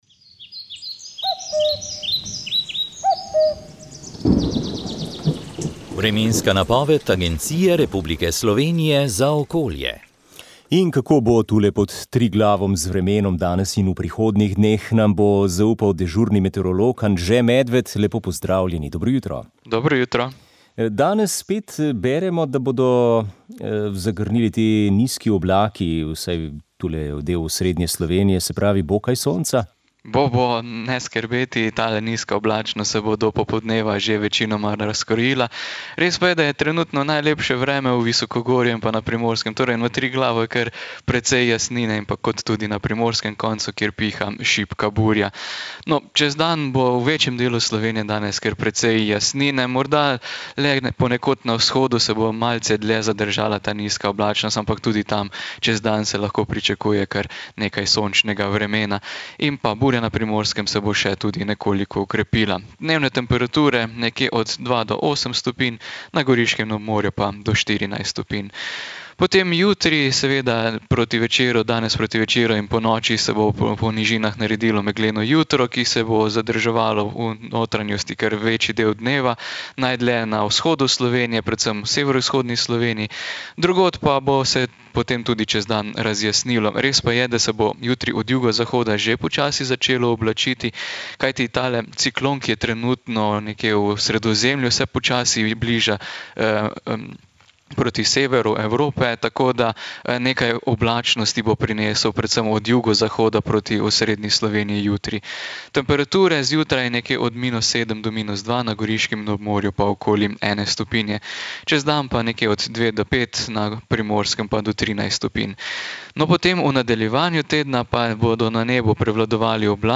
Oddaja je tokrat najprej prinesla poletni klepet z vami, dragi poslušalci, ki smo vam izpolnili tudi kakšno glasbeno željo, v studiu pa se nam je pridružil baritonist